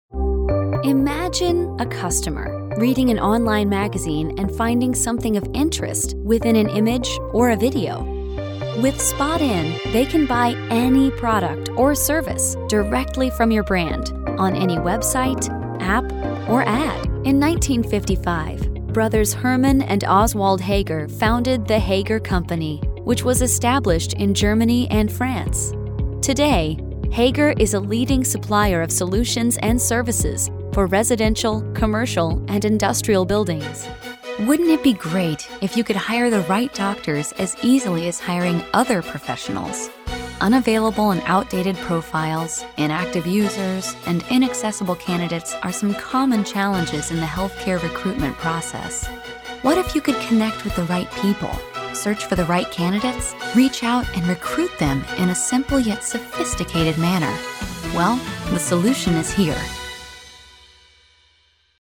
Female Voice Over, Dan Wachs Talent Agency.
Young Mom, Best Friend, Warm & Caring.
Corporate